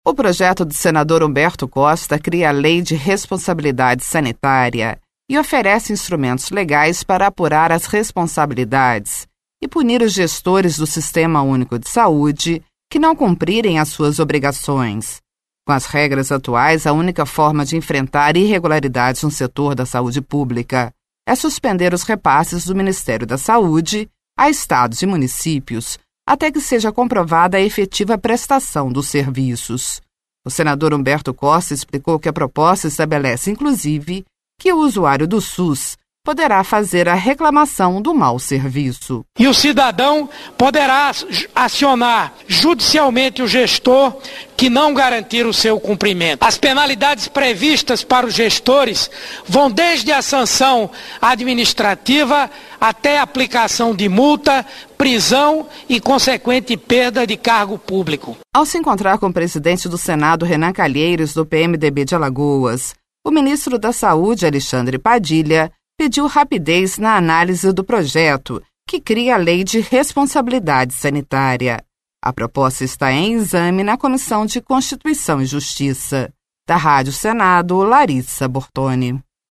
O senador Humberto Costa explicou que a proposta estabelece, inclusive, que o usuário do SUS poderá fazer a reclamação do mau serviço.